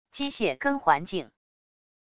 机械跟环境wav音频